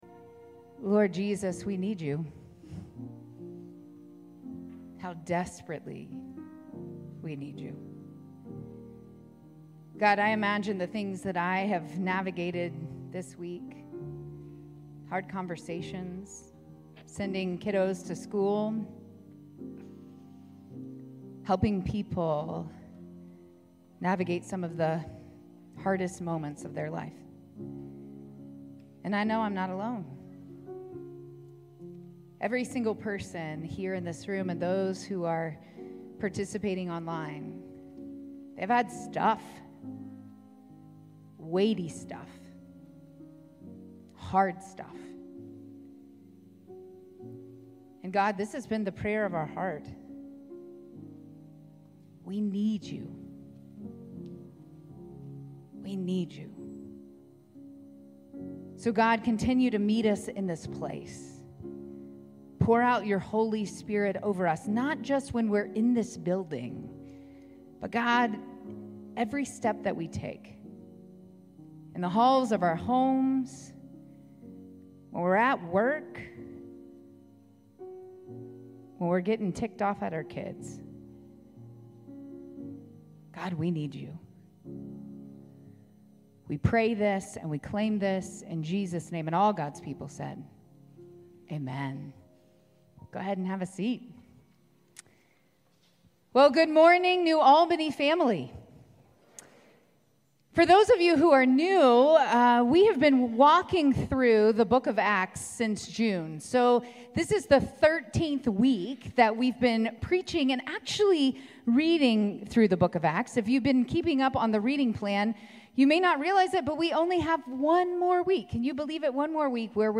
9:30 AM Redemption Worship Service August 24, 2025 – New Albany United Methodist Church